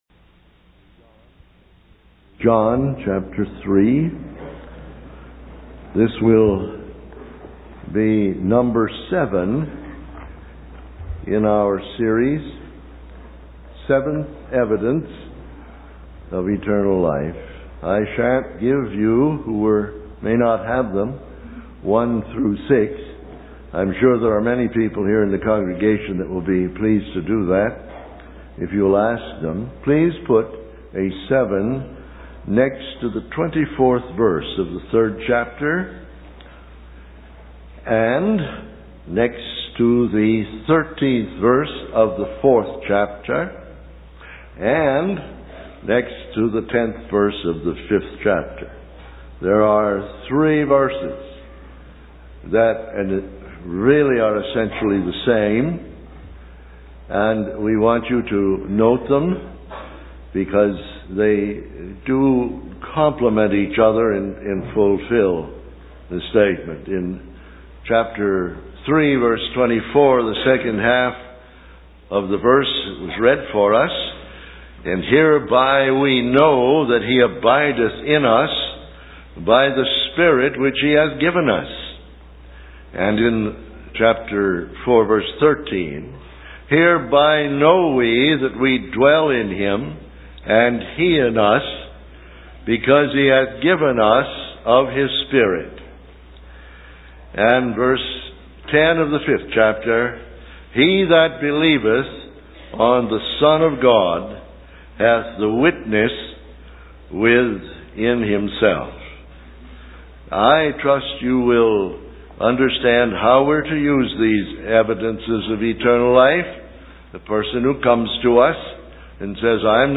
In this sermon, the speaker reflects on the impact of John Wesley's ministry and emphasizes the importance of the witness of the Spirit to the new birth.